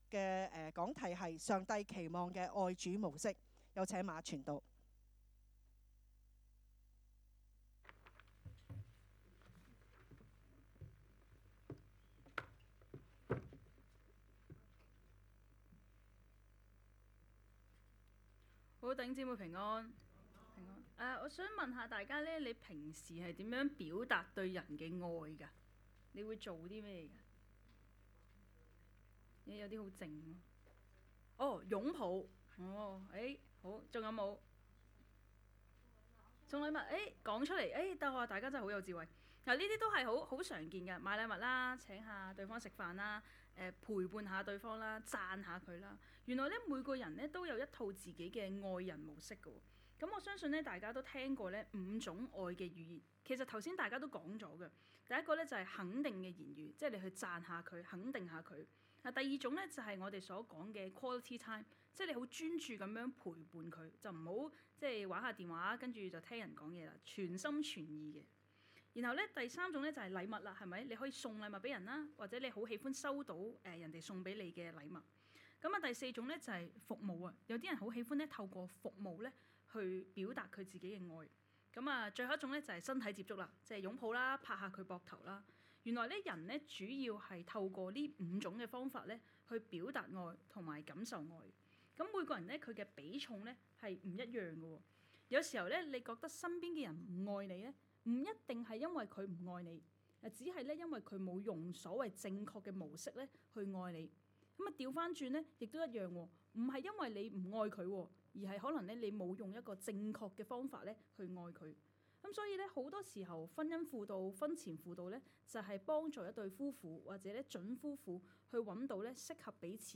講道 ： 上帝期望的愛主模式 讀經 : 可 12：28-34